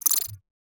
HiTech Click 2.wav